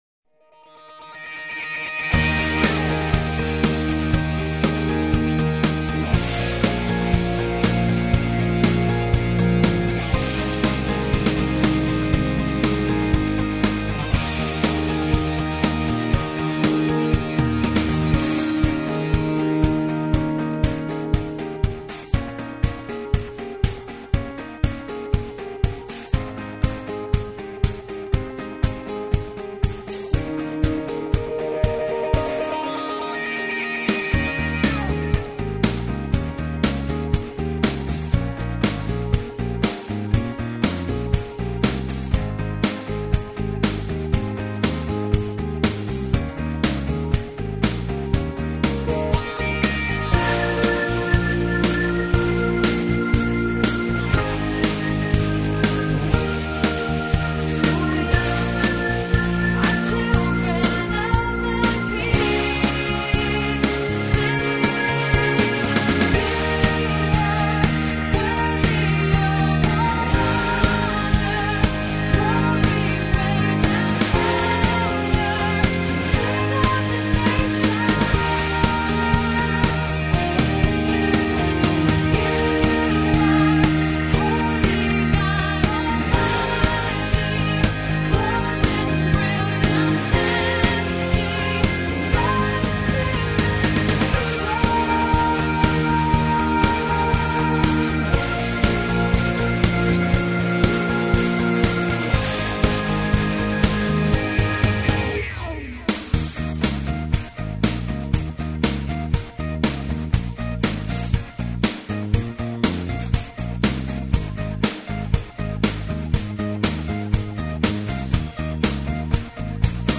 B/Trax- High Key with Backing Vocals